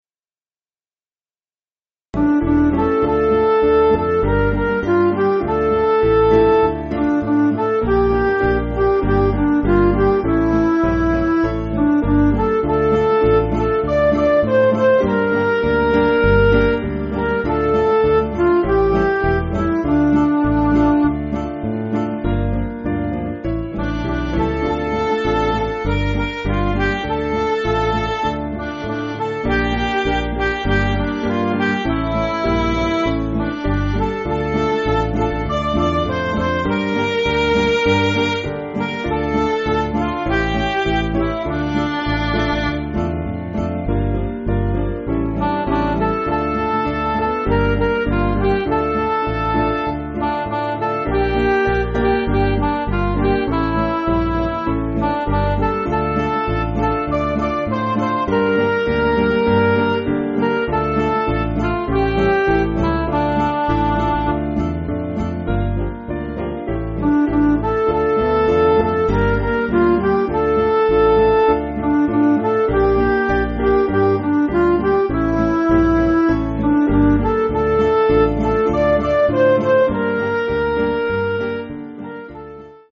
Piano & Instrumental
(CM)   7/Dm